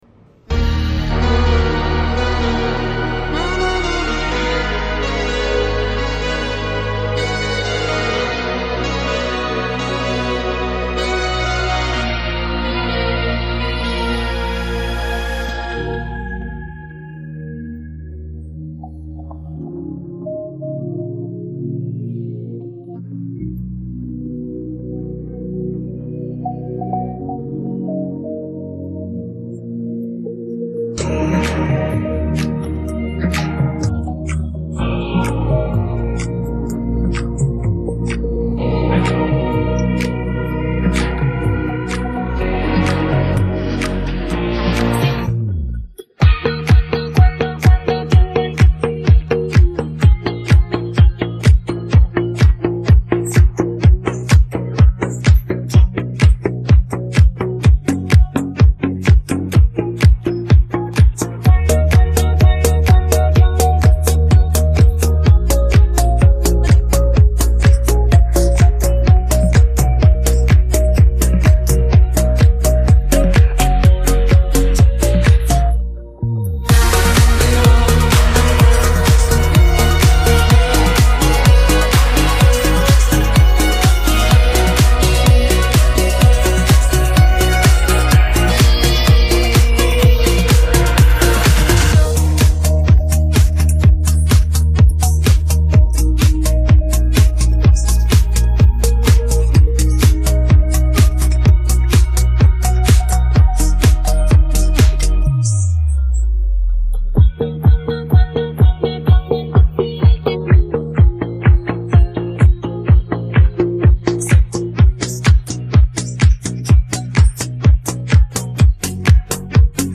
scaruca BASE